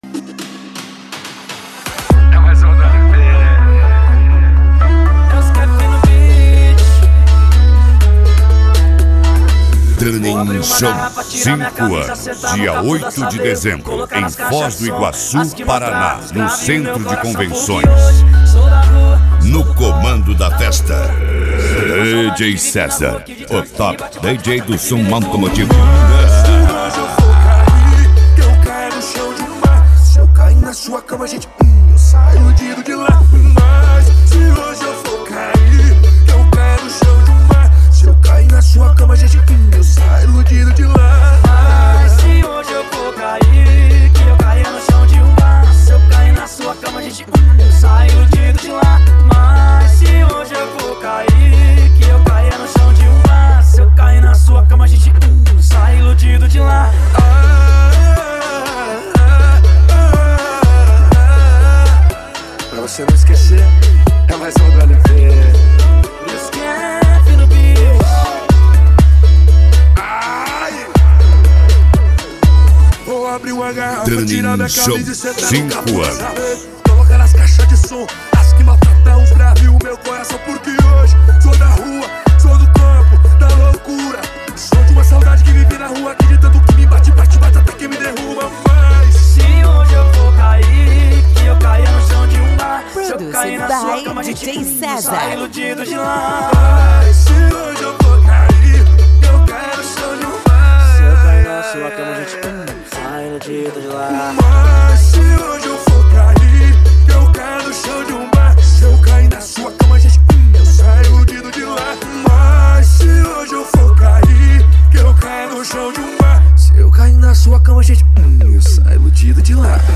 Mega Funk